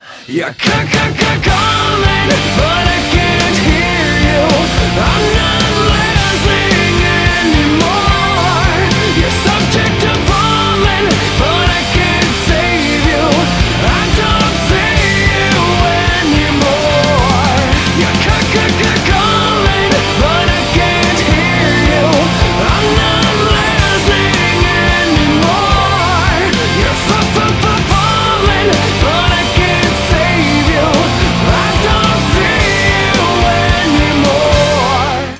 • Качество: 128, Stereo
громкие
зажигательные
Alternative Metal
Hard rock
post-grunge